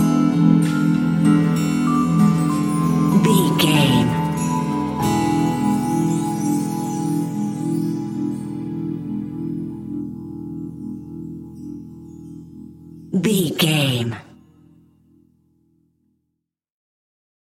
Aeolian/Minor
childrens music
instrumentals
fun
childlike
cute
happy
kids piano
kids musical instruments